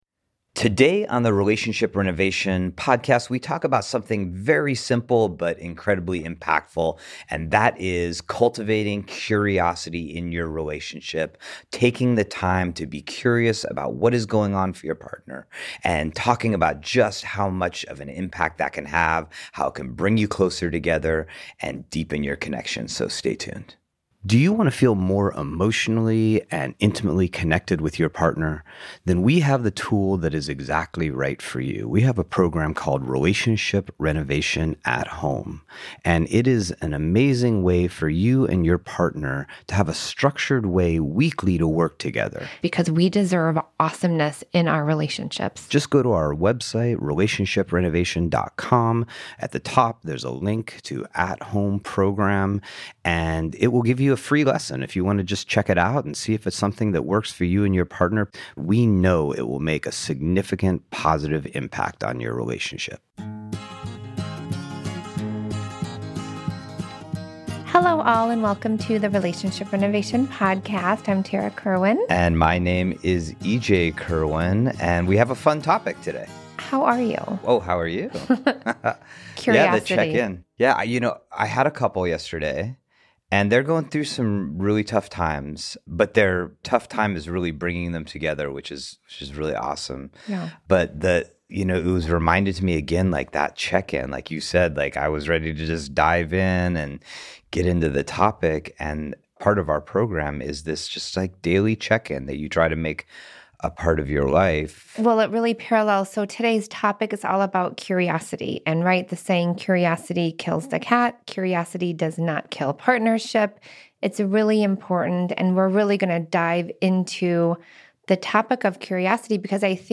engage in a profound discussion with Dr. Warren Farrell